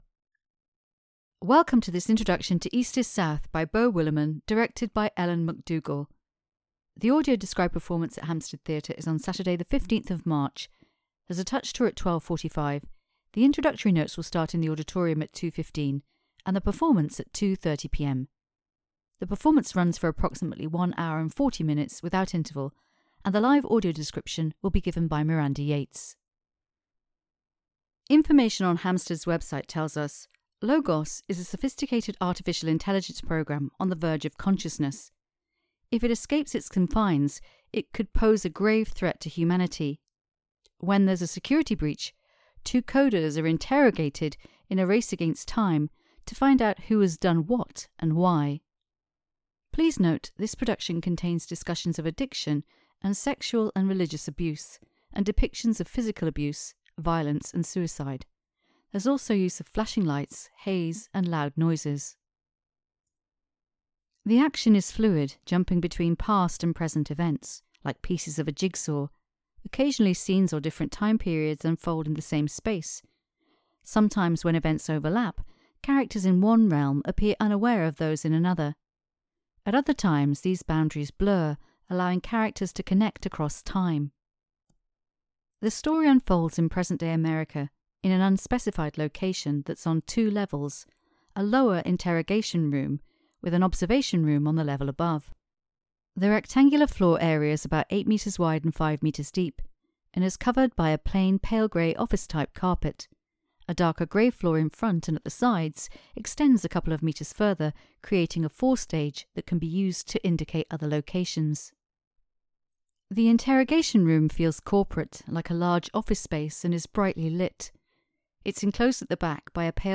An Audio Introduction is available for this production. This is a pre-recorded introduction describing the set, characters and costumes.